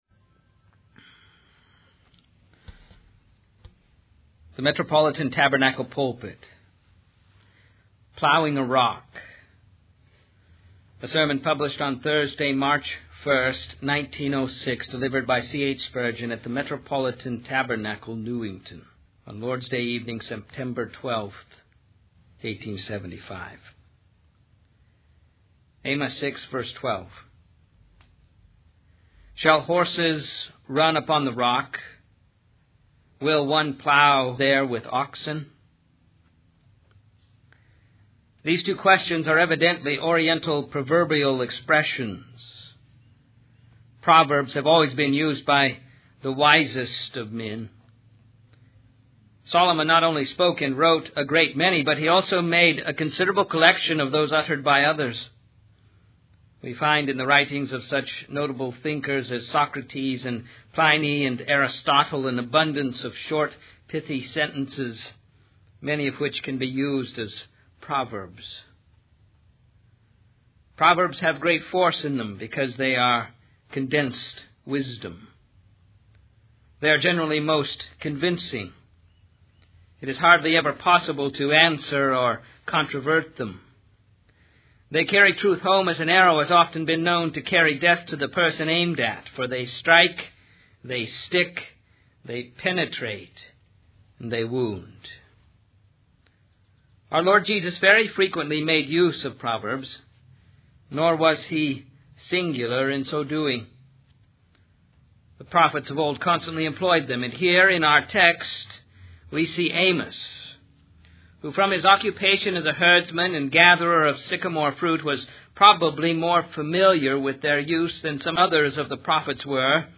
The sermon emphasizes the importance of the gospel and the need for individuals to accept it in order to find true satisfaction. The preacher expresses frustration with those who refuse to accept the gospel despite repeated explanations and attempts to reach them.